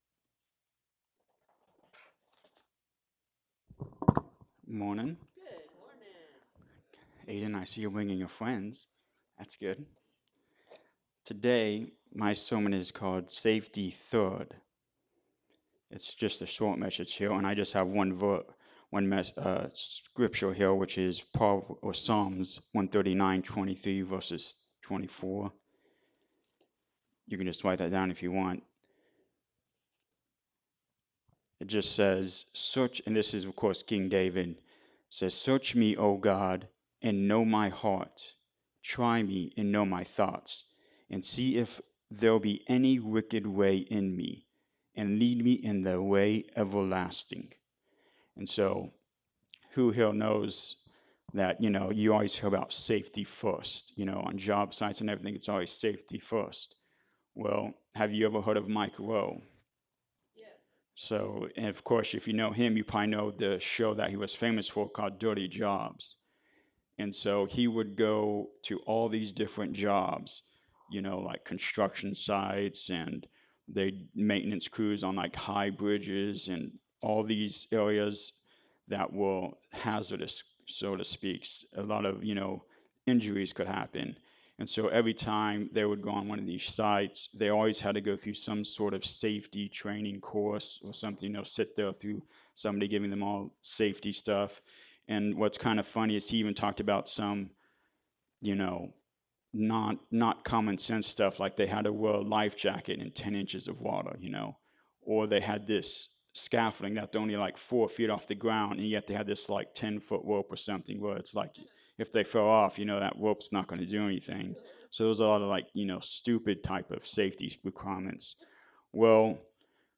Sunday-Sermon-for-May-4-2025.mp3